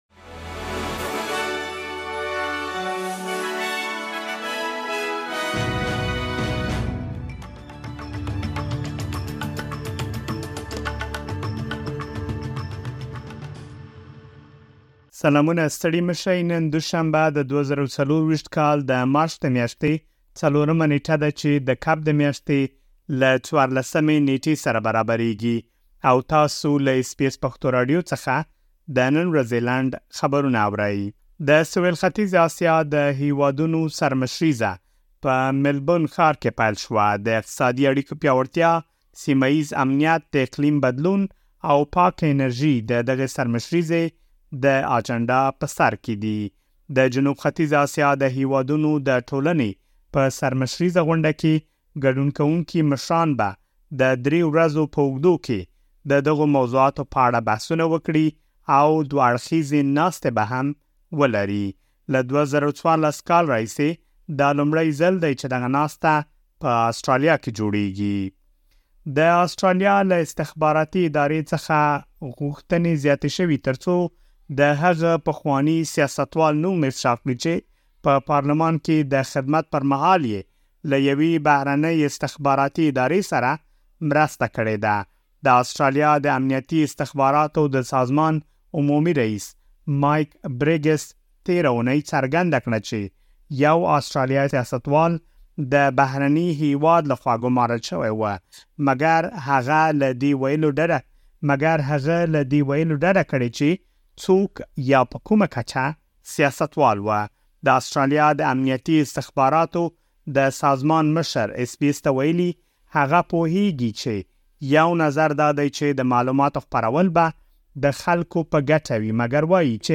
د اس بي اس پښتو راډیو د نن ورځې لنډ خبرونه|۴ مارچ ۲۰۲۴
د اس بي اس پښتو راډیو د نن ورځې لنډ خبرونه دلته واورئ.